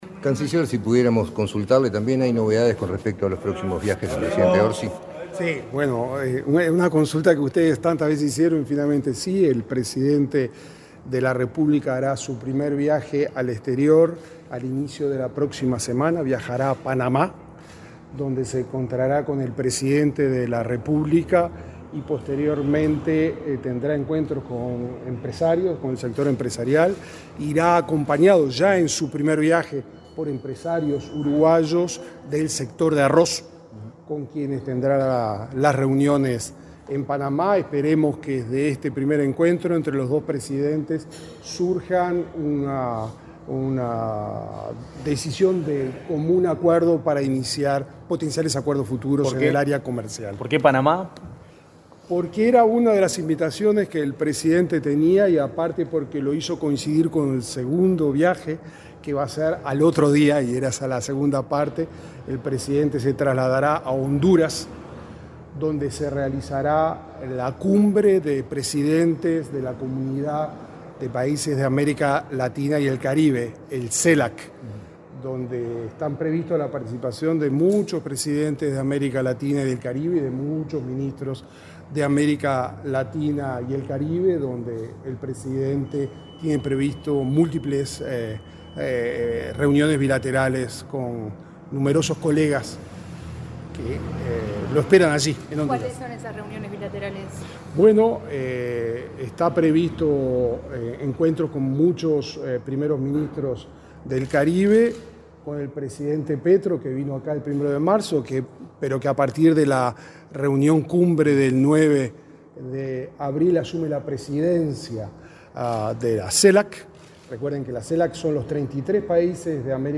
Declaraciones del canciller Mario Lubetkin